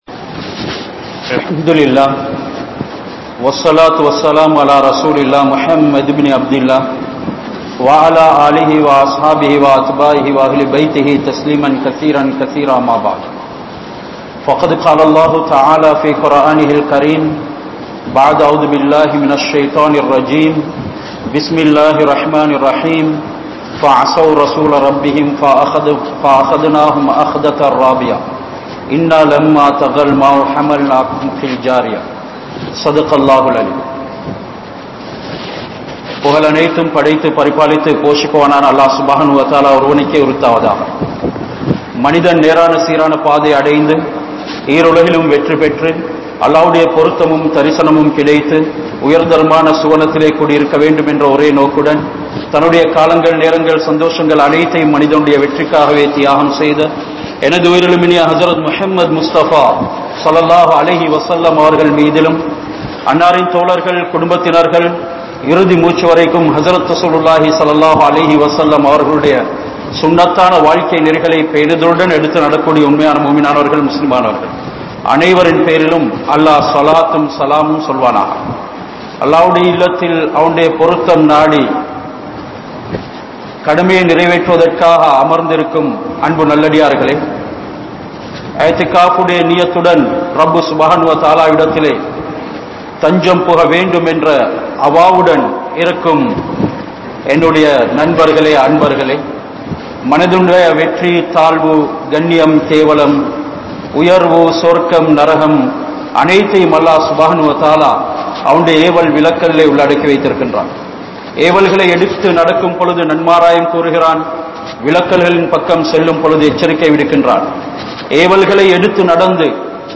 Allah`vin Athisayamaana Padaipuhal (அல்லாஹ்வின் அதிசயமான படைப்புகள்) | Audio Bayans | All Ceylon Muslim Youth Community | Addalaichenai
Kanampittya Masjithun Noor Jumua Masjith